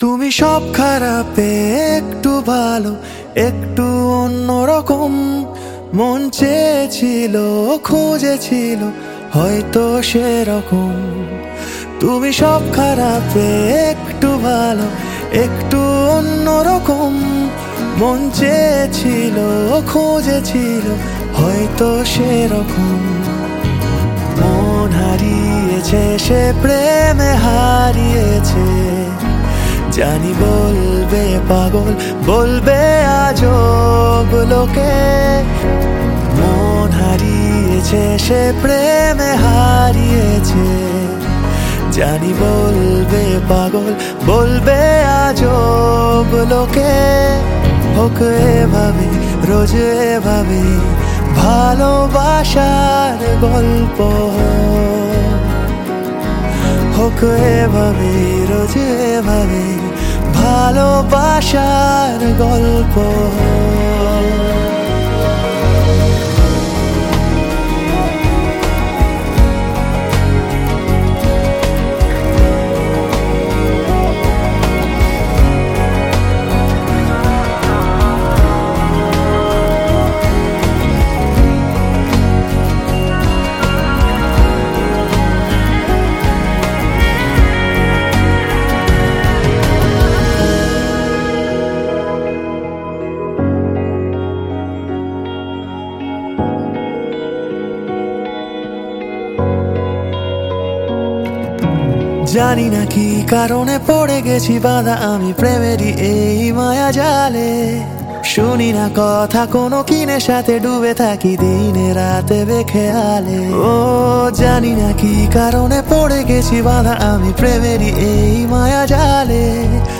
Bengali